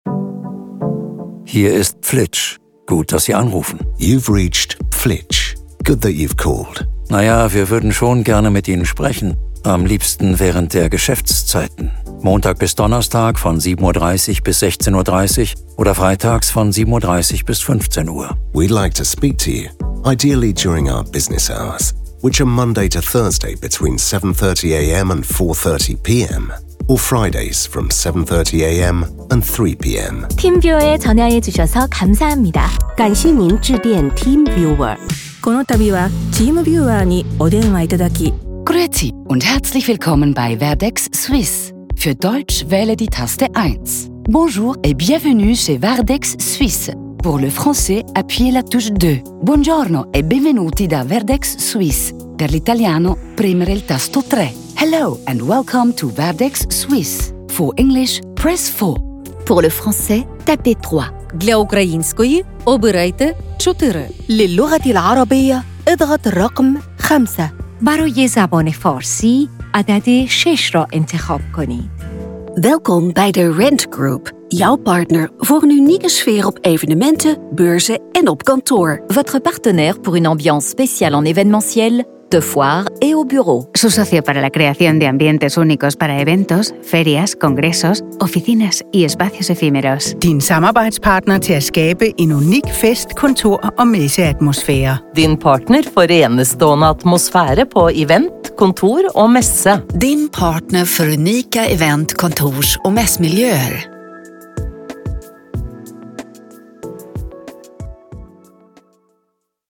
Mehrsprachige Ansagen für globale Kunden